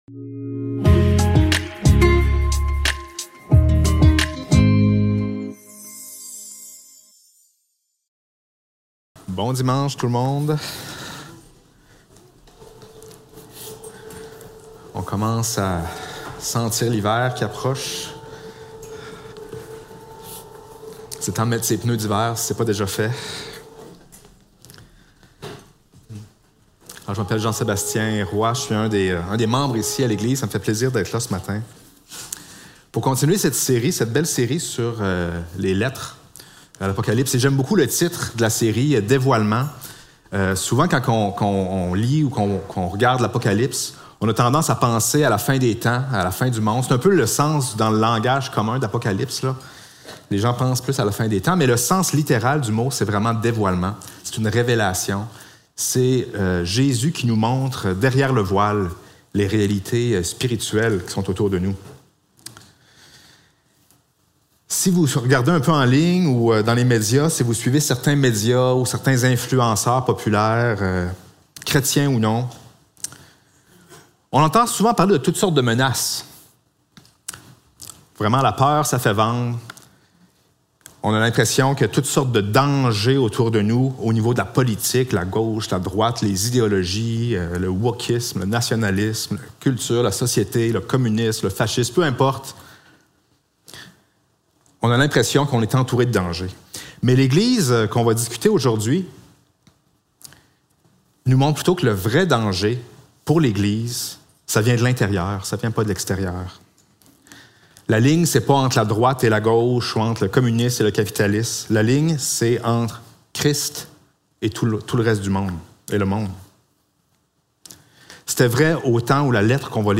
Apocalypse 2.18-29 Service Type: Célébration dimanche matin Description